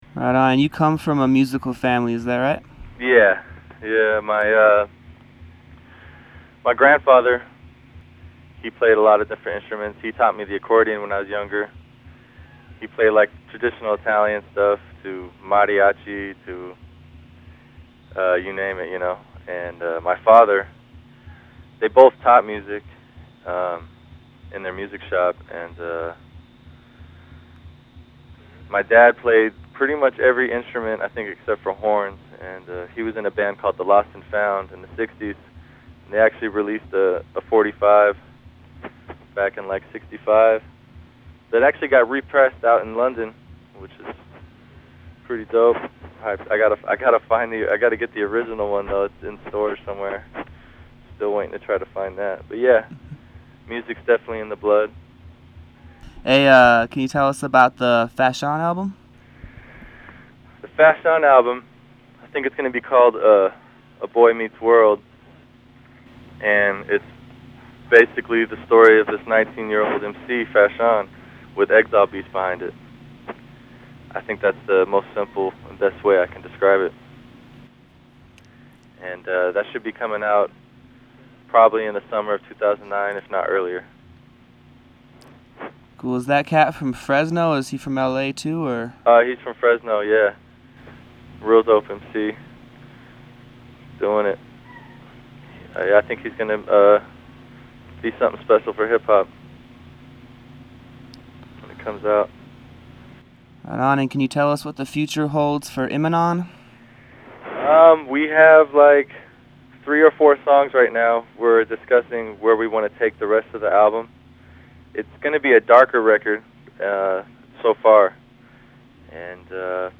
exile_interview_on_basementalism_part_2.mp3